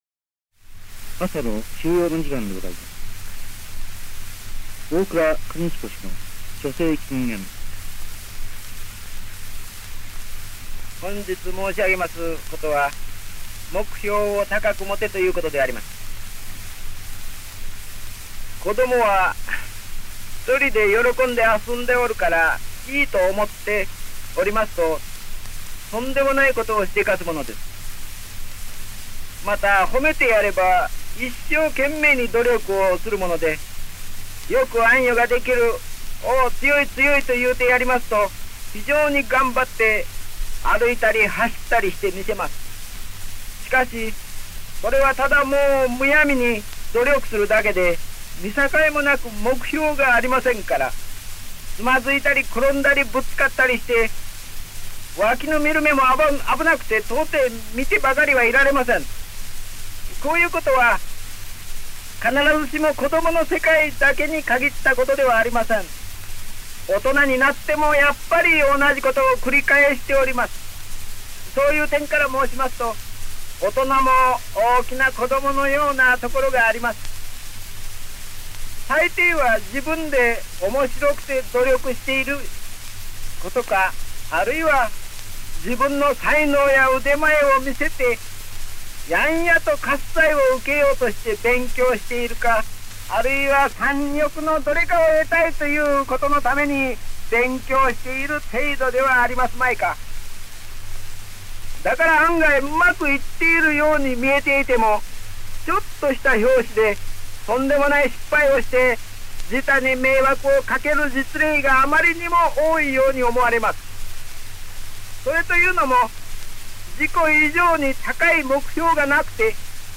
本音声は、その第３回目（昭和12年3月28日）のラジオ放送を録音したSP盤レコードをデジタル化したものです。